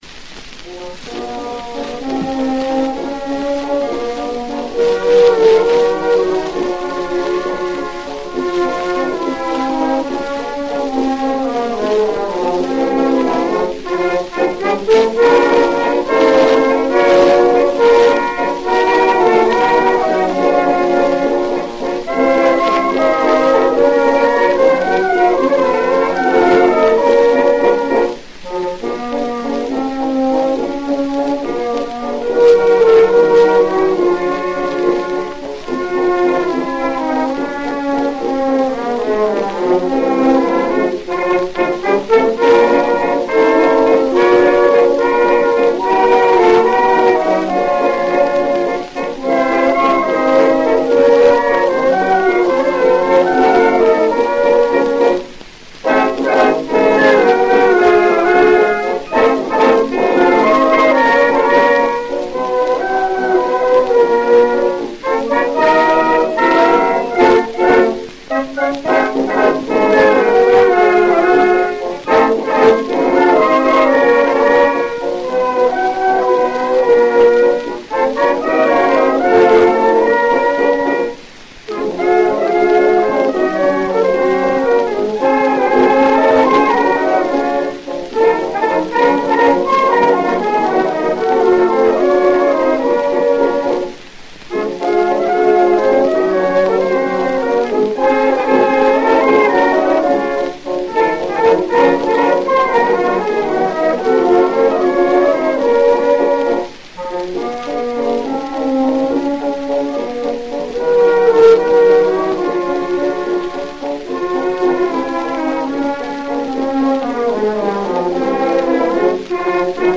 Тот вальс